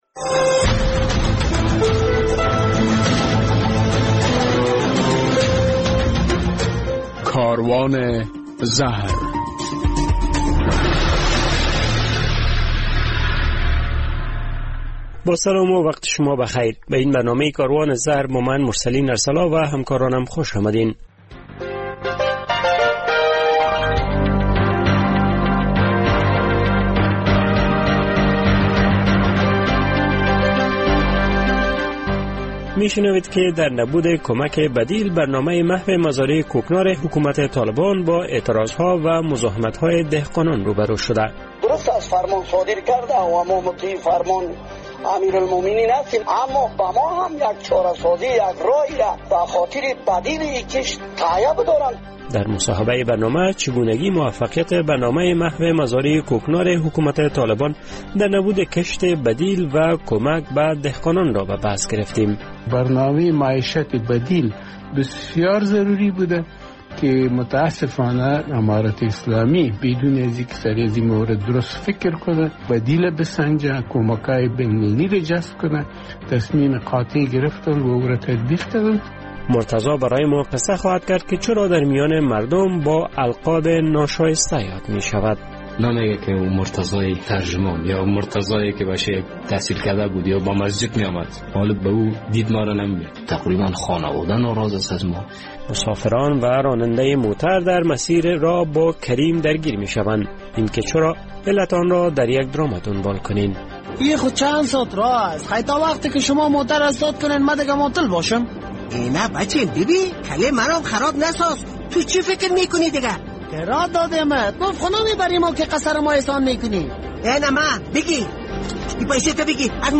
در این برنامه کاروان زهر خواهید شنید که عدم ارایه کمک بدیل کوکنار به دهقانان آنان را واداشته تا در برابر برنامه محو مزارع کوکنارایستاده گی کنند. در مصاحبه برنامه چگونگی موانع در برابر برنامه مبارزه حکومت طالبان با کشت کوکنار را به بررسی گرفته ایم.